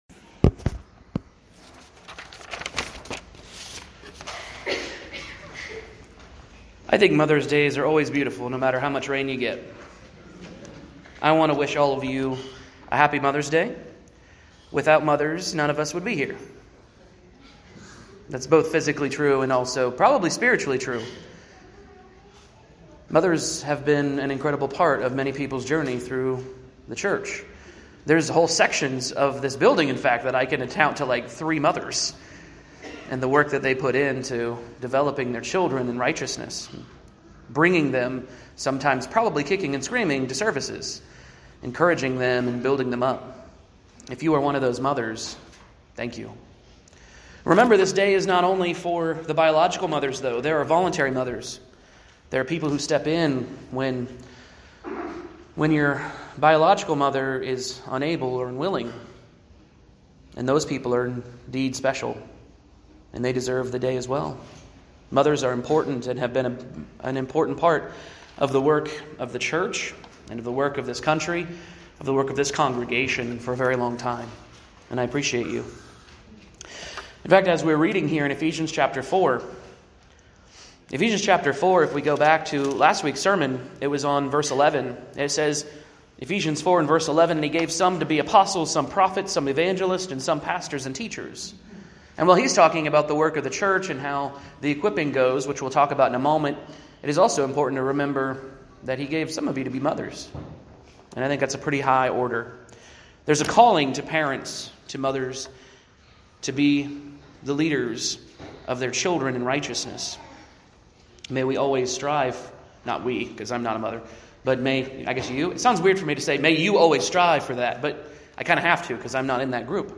최신 항목은 A sermon on church work입니다.